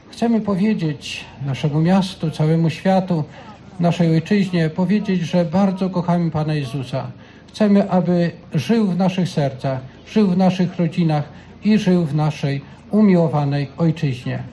Wydarzenie rozpoczęło się od Mszy świętej o godzinie 12.00, a później nastąpił przemarsz ulicami miasta pod Ołtarz Papieski przy Sanktuarium Miłosierdzia Bożego.
Pan Bóg jest naszą miłością mówił Biskup Pomocniczy Diecezji Łomżyńskiej, Tadeusz Bronakowski: